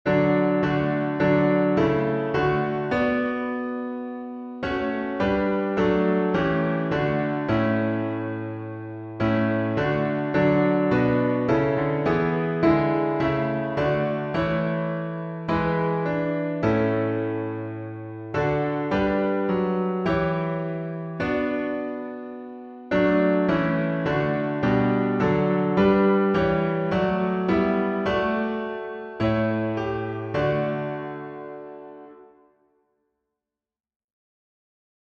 Key signature: D major (2 sharps) Time signature: 4/4 Meter: Irregular